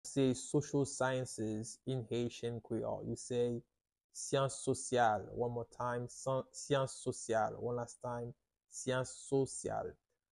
How to say "Social Sciences" in Haitian Creole - "Syans sosyal" pronunciation by a native Haitian Creole tutor
“Syans sosyal” Pronunciation in Haitian Creole by a native Haitian can be heard in the audio here or in the video below:
How-to-say-Social-Sciences-in-Haitian-Creole-Syans-sosyal-pronunciation-by-a-native-Haitian-Creole-tutor.mp3